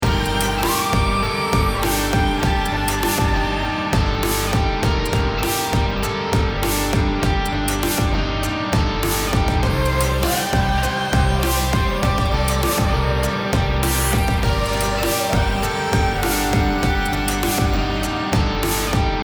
528hz BPM100-109 Game Instrument Soundtrack インストルメント
BPM 100